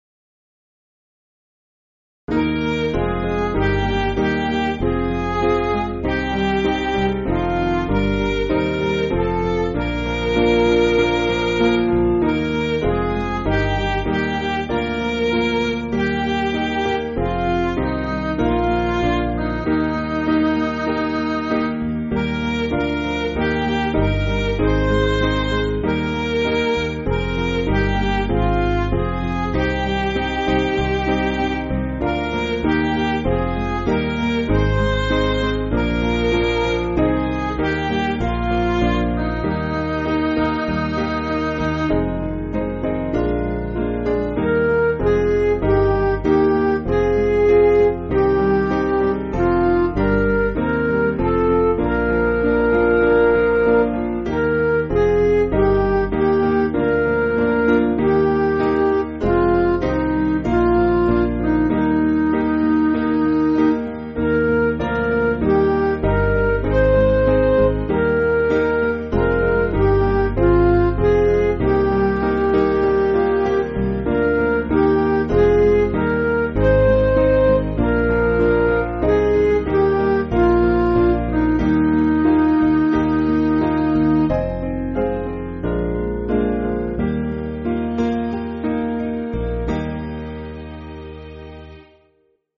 Piano & Instrumental
(CM)   2/Eb